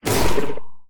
Minecraft Version Minecraft Version snapshot Latest Release | Latest Snapshot snapshot / assets / minecraft / sounds / mob / warden / attack_impact_1.ogg Compare With Compare With Latest Release | Latest Snapshot
attack_impact_1.ogg